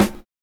Snare Groovin 2.wav